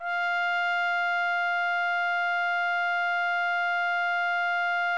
TRUMPET   24.wav